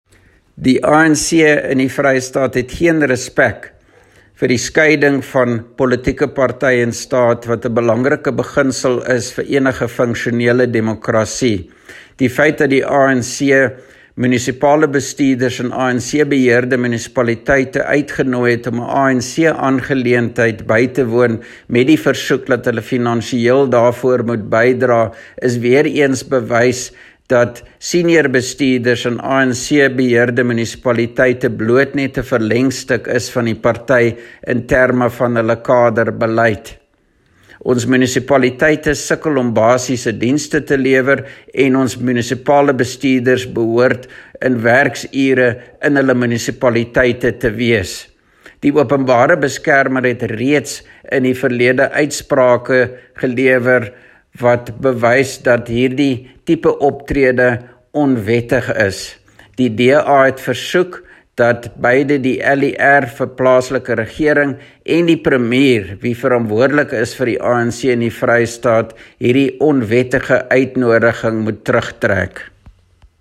Afrikaans soundbites by Roy Jankieslohn MPL and